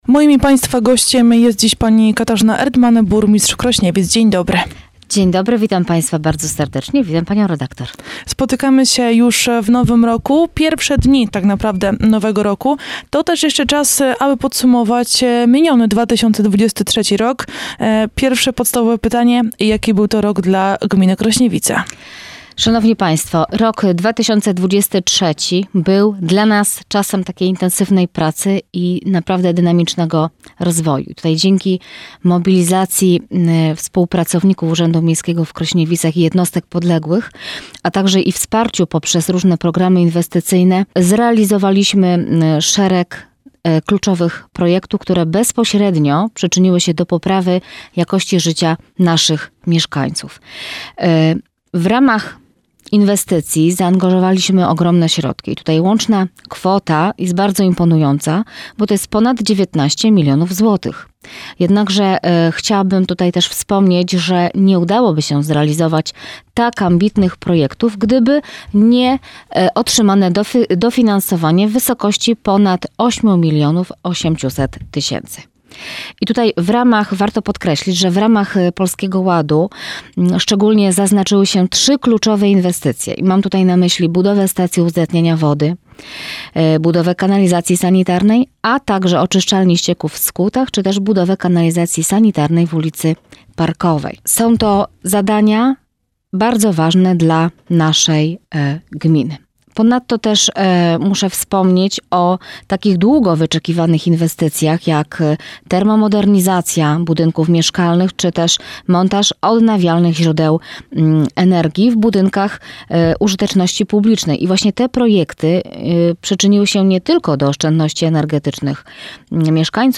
[Radio Q] Katarzyna Erdman była gościem Radia Q w dniu 05.01.2024 | Krośniewice - Gmina z pomysłem